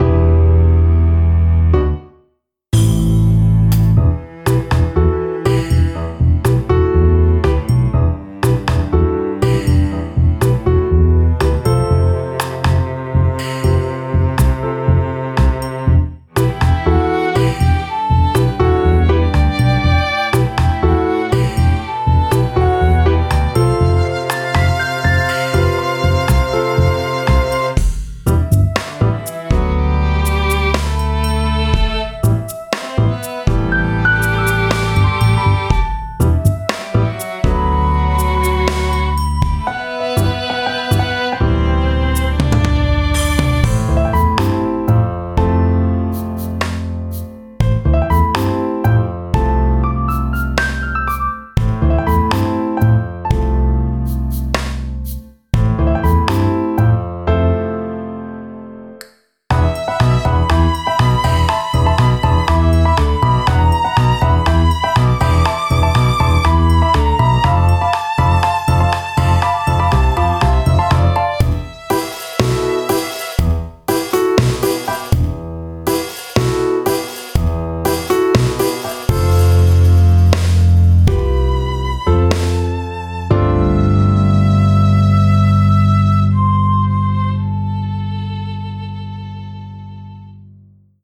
A short, quirky track alternating from tense to smooth.